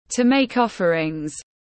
Cúng lễ tiếng anh gọi là to make offerings, phiên âm tiếng anh đọc là /tu: meɪk ˈɒf.ər.ɪŋ/